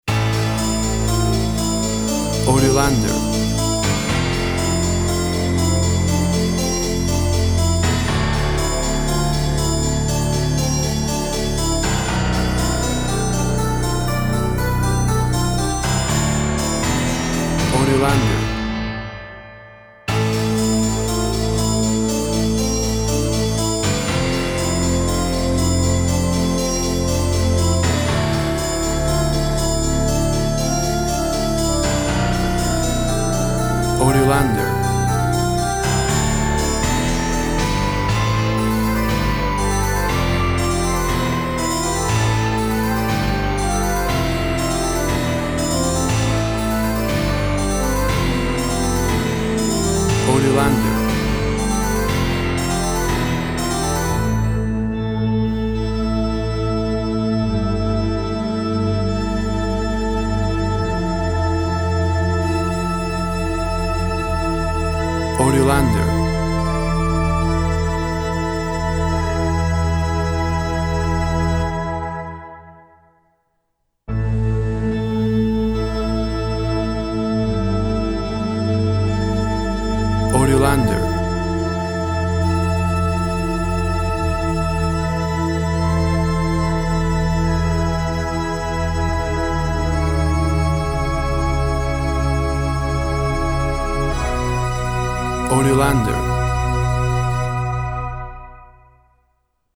Tempo (BPM) 124/72